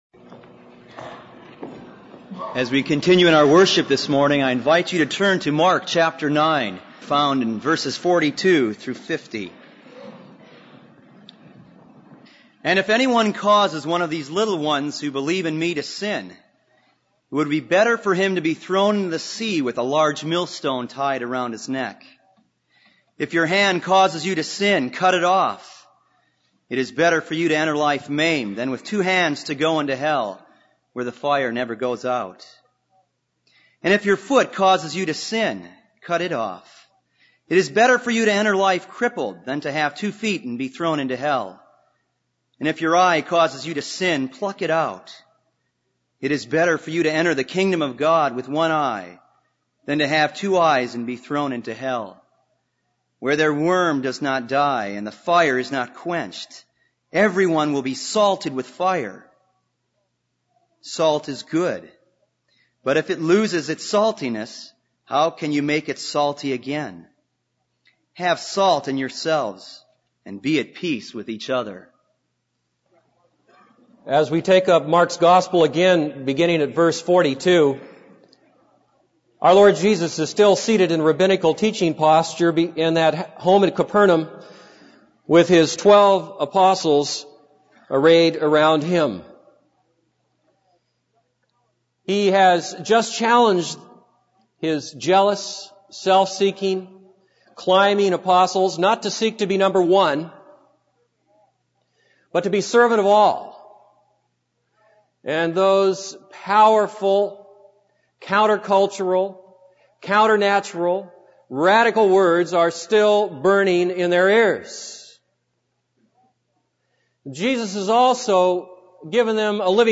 This is a sermon on Mark 9:42-50.